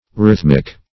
Rhythmic \Rhyth"mic\ (-m[i^]k), Rhythmical \Rhyth"mic*al\